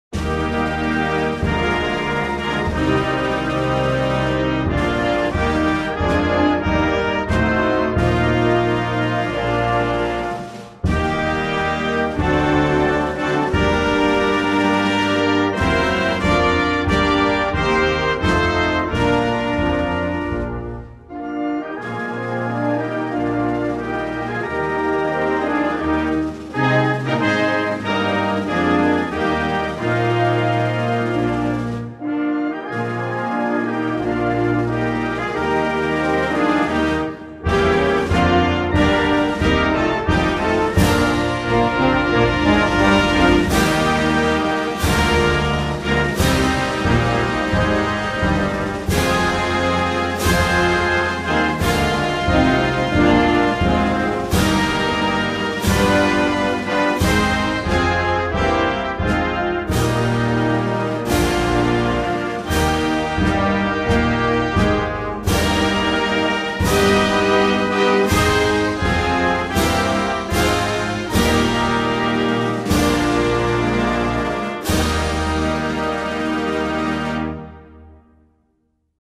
Музыка Канады без слов